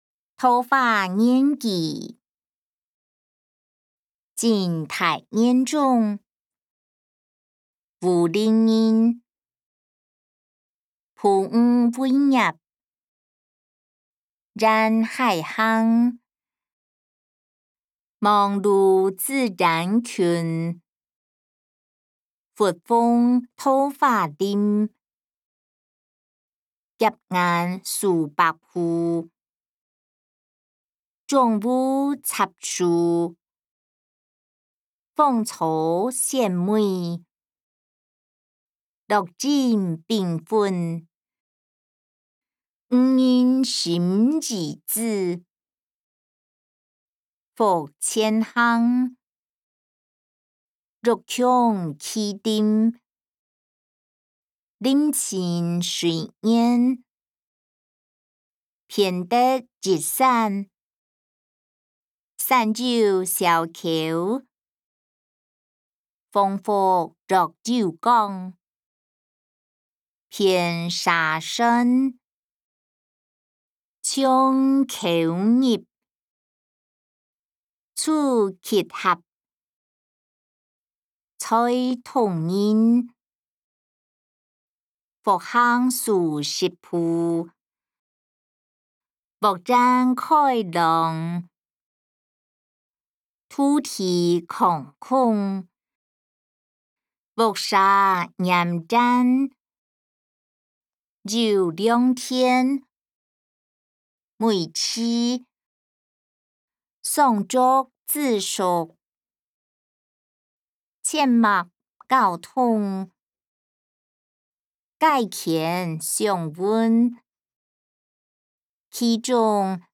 歷代散文-桃花源記音檔(海陸腔)